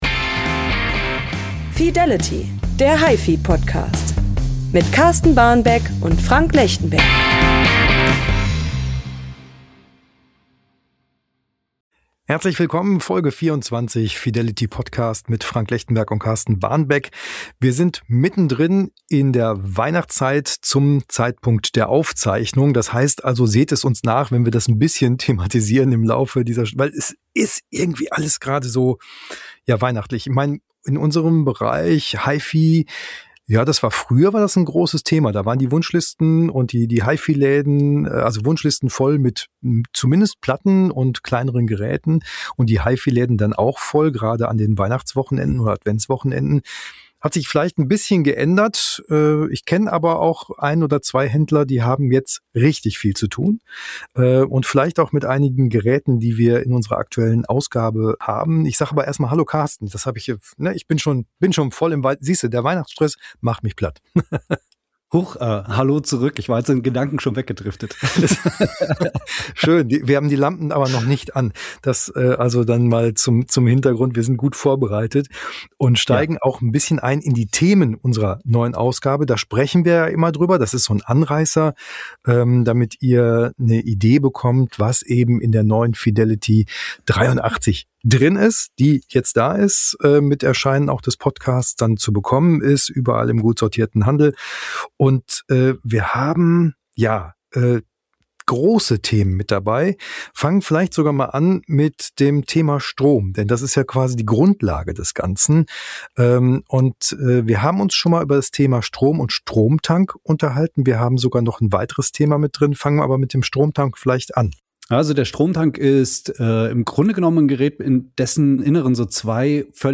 Geräte, Gerüchte und gute Musik! Der gepflegte Talk am Kaffeetisch zu unserem liebsten Hobby.